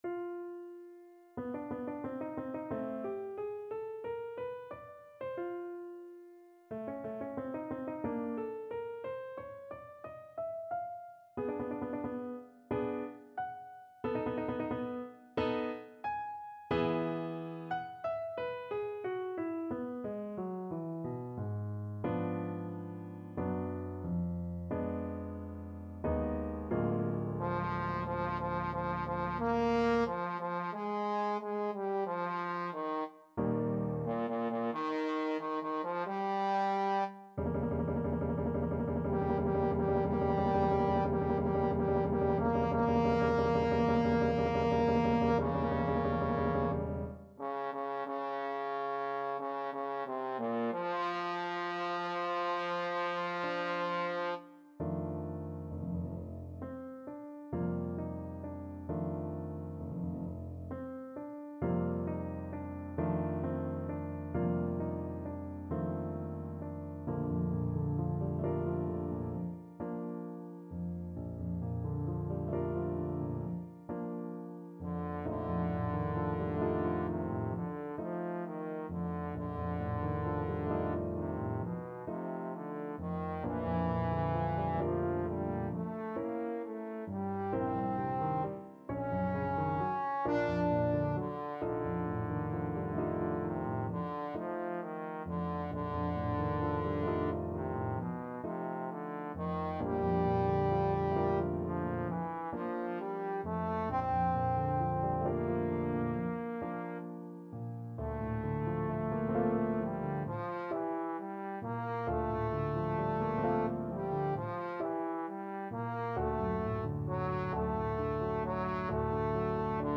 Trombone
4/4 (View more 4/4 Music)
Bb major (Sounding Pitch) (View more Bb major Music for Trombone )
Moderato =90
Classical (View more Classical Trombone Music)